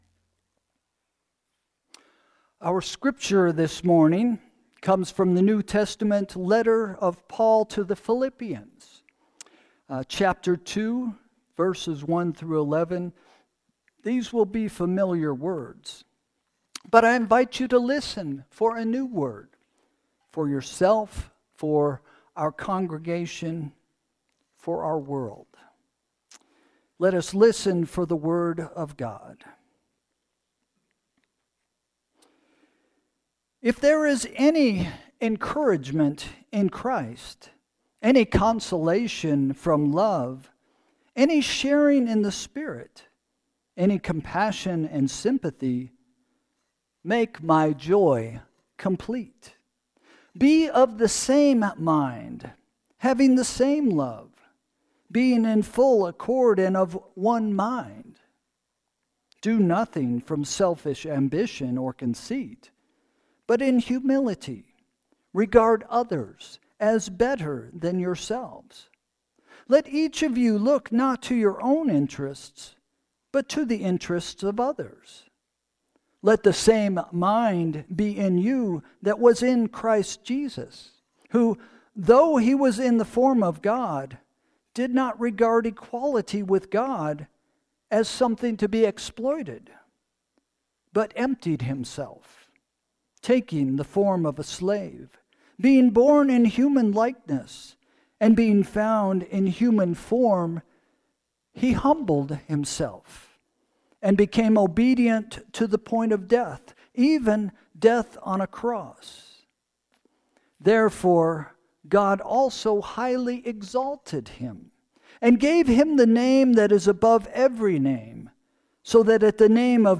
Sermon – December 22, 2024 – “Joy to the World” – First Christian Church